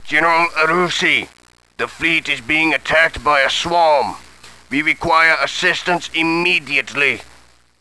Human Male, Age 51